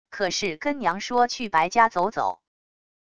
可是跟娘说去白家走走wav音频生成系统WAV Audio Player